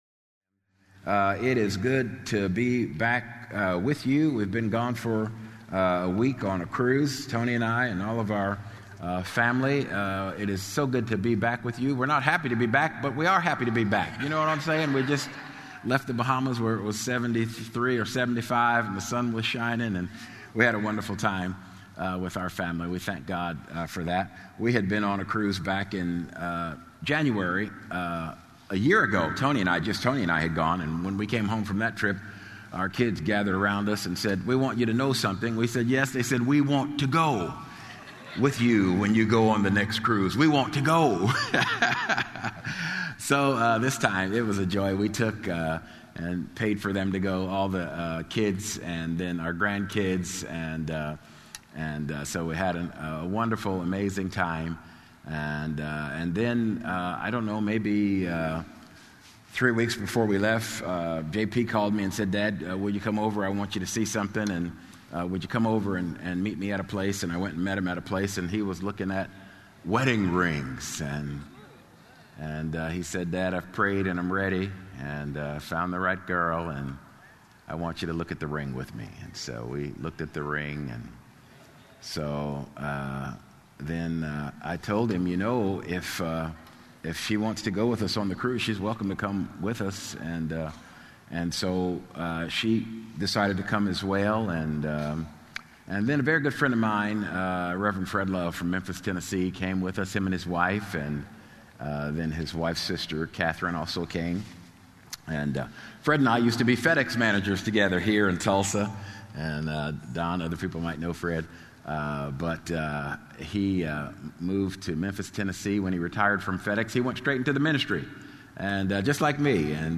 Podcasts for RHEMA Bible Church services held at the Broken Arrow, OK campus.